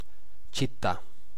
Ääntäminen
Synonyymit cité commune localité Ääntäminen France: IPA: [yn vil] France: IPA: /vil/ Quebec: IPA: /vɪl/ Haettu sana löytyi näillä lähdekielillä: ranska Käännös Ääninäyte Substantiivit 1. città {f} Suku: f .